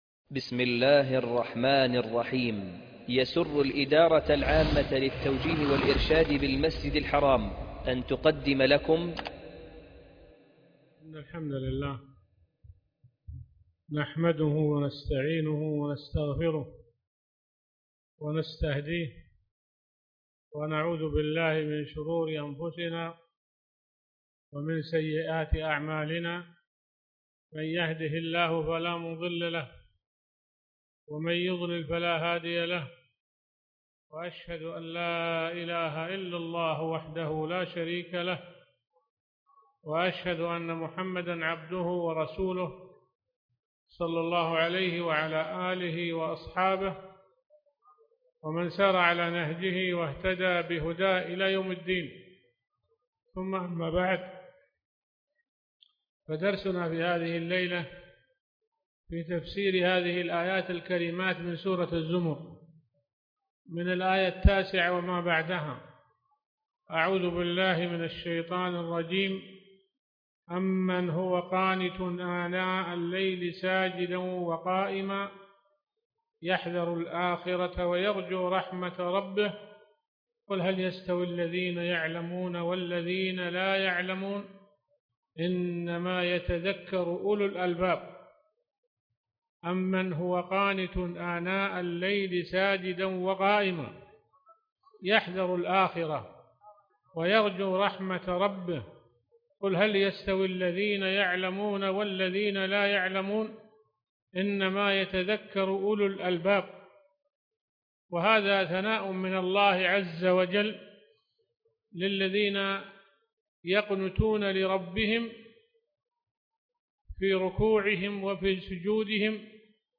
الدرس 15 ( مختصر تفسير ابن كثير